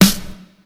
kits/RZA/Snares/GVD_snr (40).wav at main
GVD_snr (40).wav